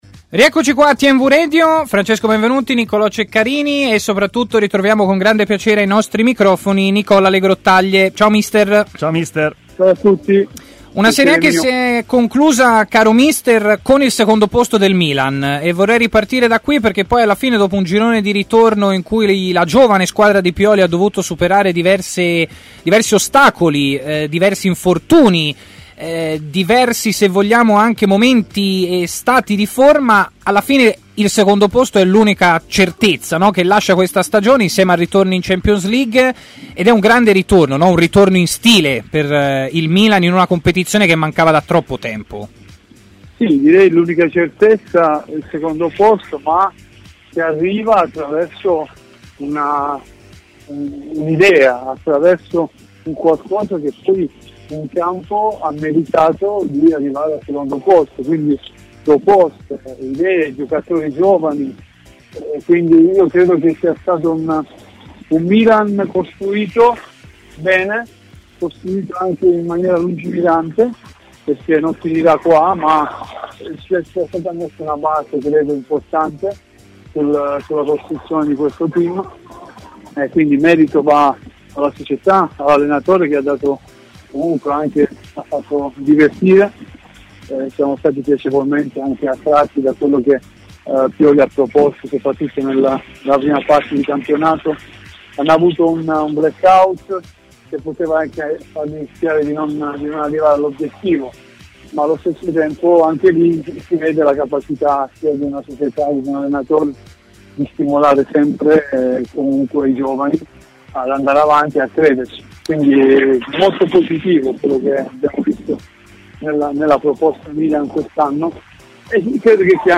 L'ex difensore Nicola Legrottaglie, oggi allenatore, parla così a TMW Radio, nel corso della trasmissione Stadio Aperto